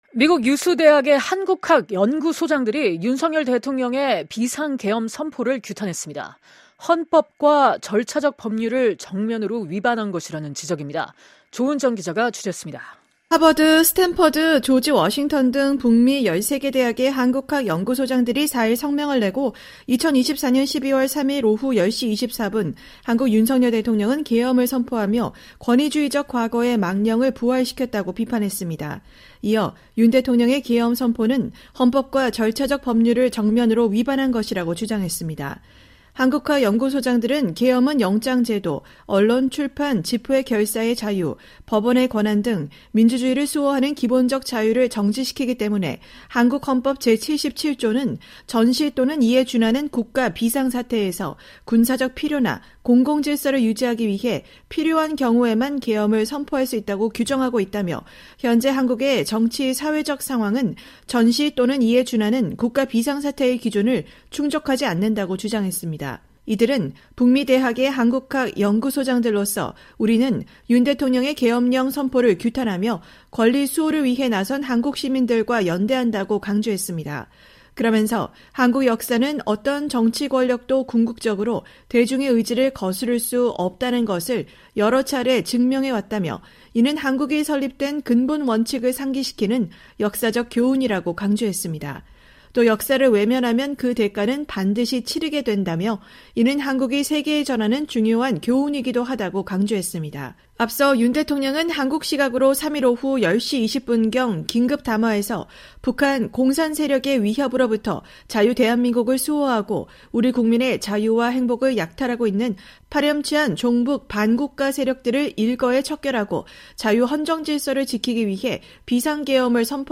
기자가 보도합니다.
화상 인터뷰를 진행했다.